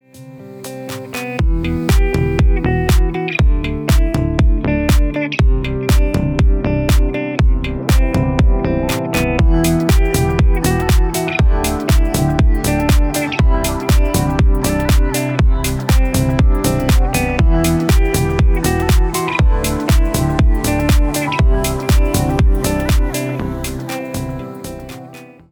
• Качество: 320, Stereo
гитара
deep house
спокойные
без слов
красивая мелодия
расслабляющие